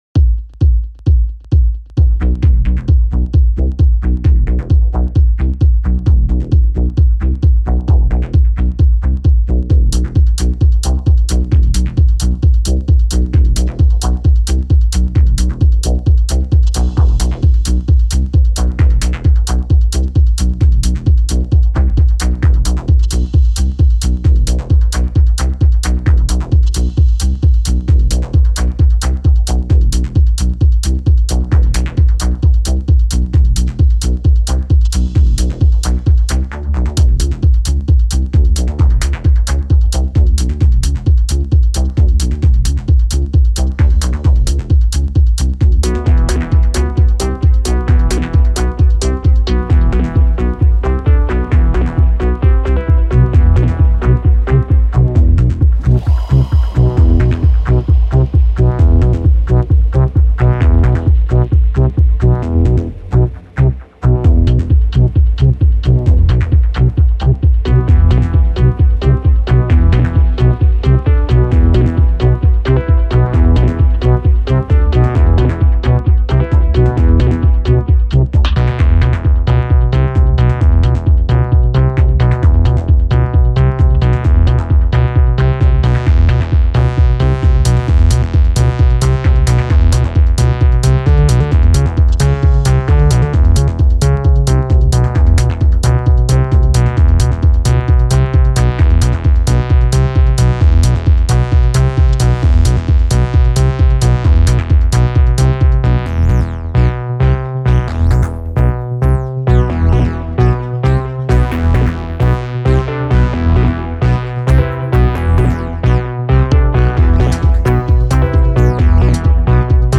Auf 132 BPM beschleunigen.